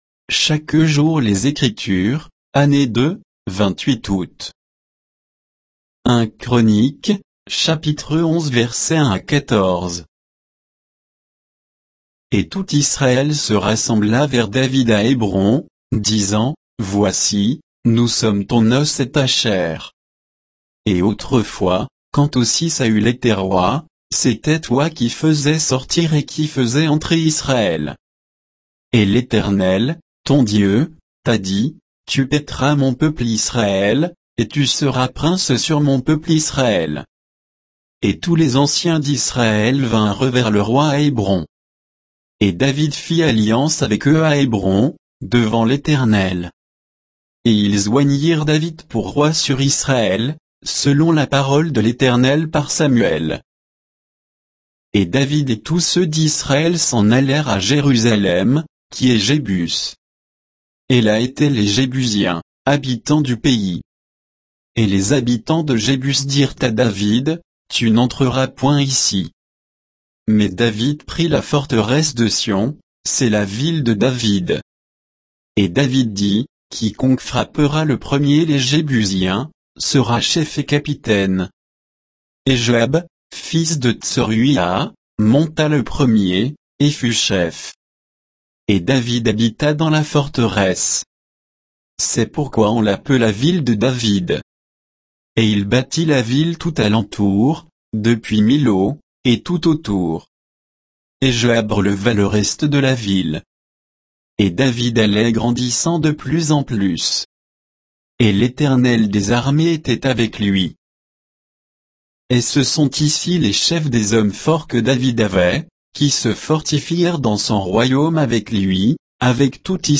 Méditation quoditienne de Chaque jour les Écritures sur 1 Chroniques 11